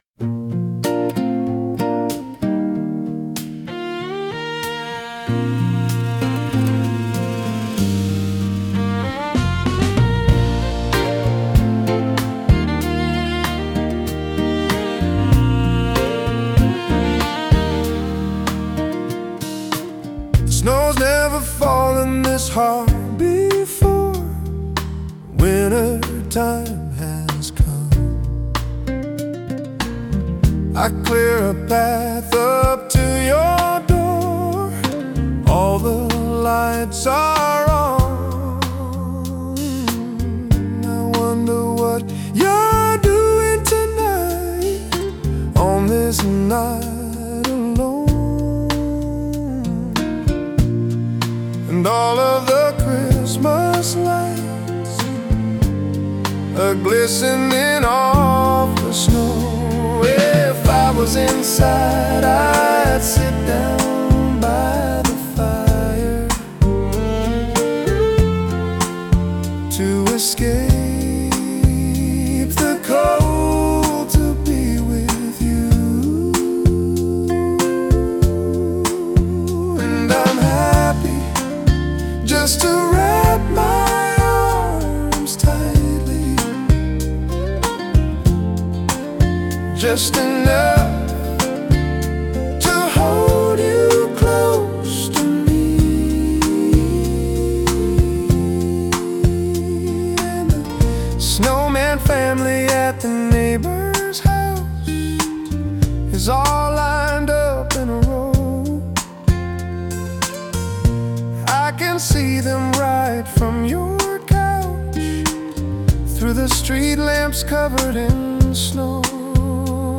Version: Coffee House / Acoustic Cover
• Primary: Acoustic / Coffee House Christmas
• Laid-back, intimate acoustic arrangement
• Emphasis on melody and emotion over production flash
• Estimated BPM: ~68–74 BPM
• Slow to mid-tempo, relaxed and steady